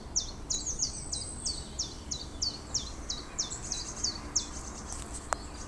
Vogelstimmen: Zaunkönig,
Zilpzap,
Zilpzalp.wav